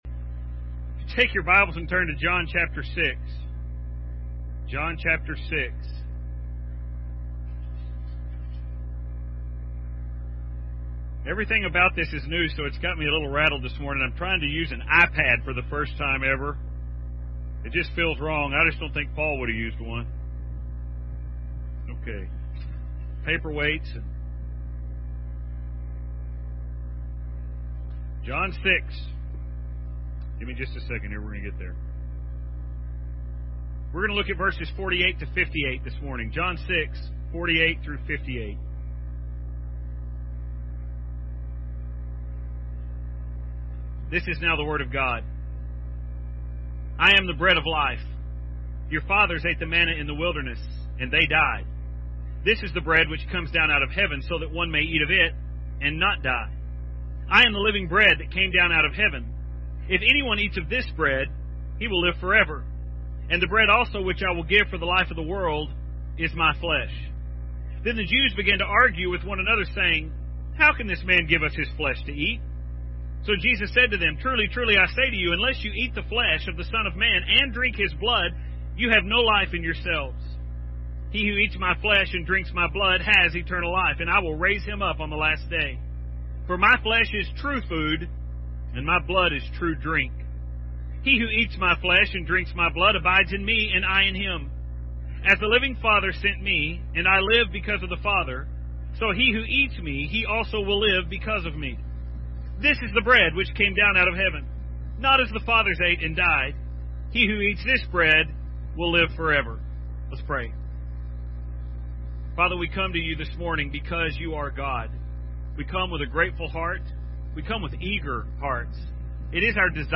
This morning we gather here on this Easter Sunday, And we do so because here we commemorate the resurrection of Christ.